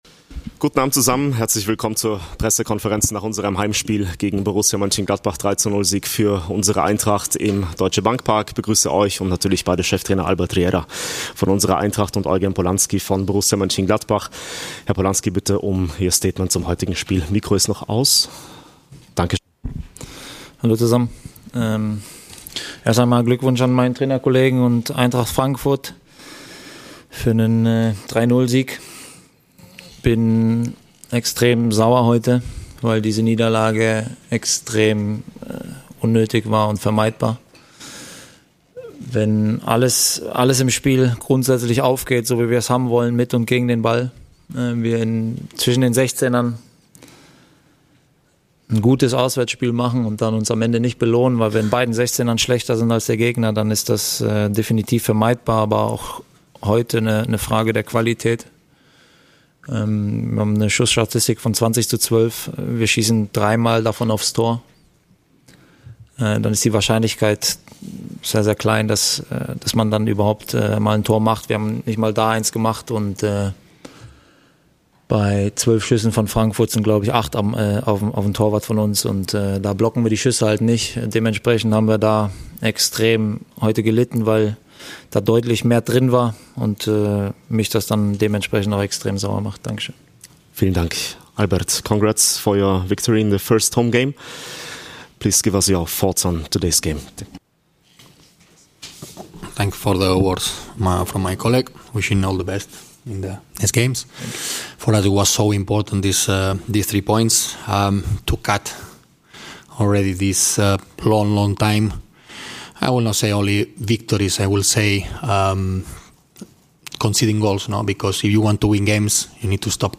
Die Pressekonferenz nach dem Heimspiel gegen Borussia Mönchengladbach.